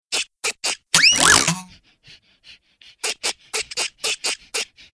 backflip.ogg